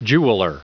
Prononciation du mot jeweler en anglais (fichier audio)
Prononciation du mot : jeweler
jeweler.wav